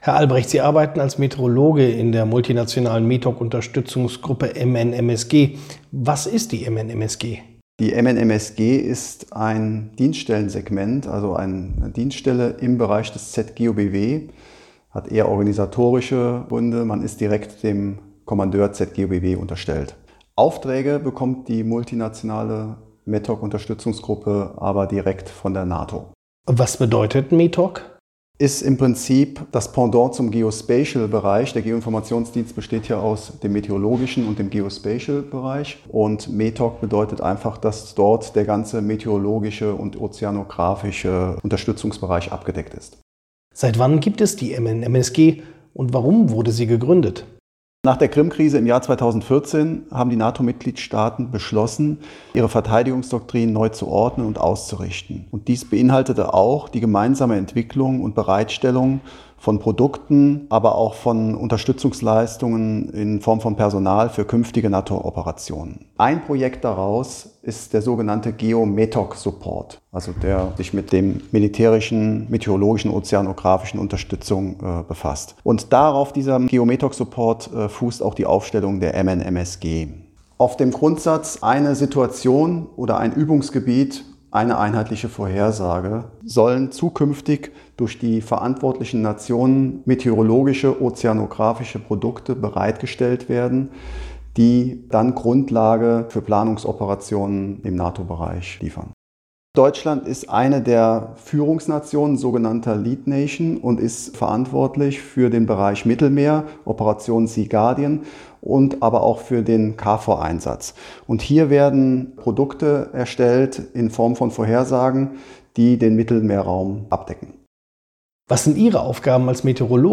interview-audio-metoc-data.mp3